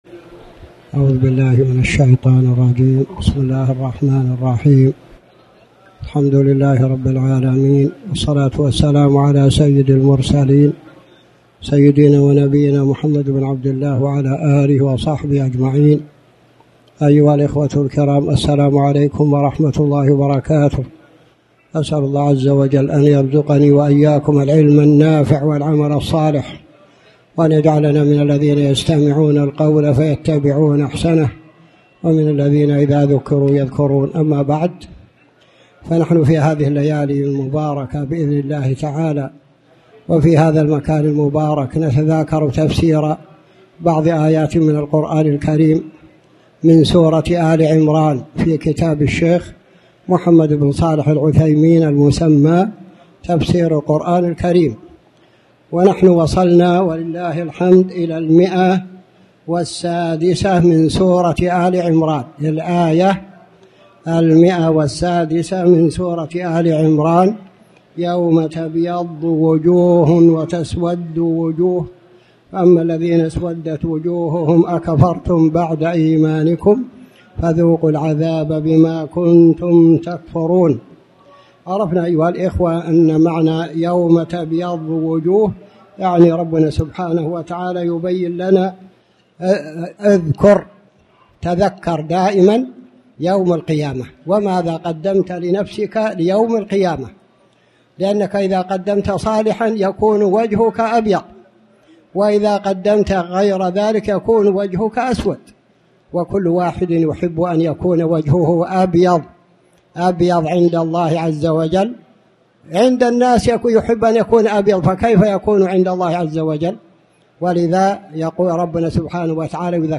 تاريخ النشر ٢٣ ربيع الأول ١٤٣٩ هـ المكان: المسجد الحرام الشيخ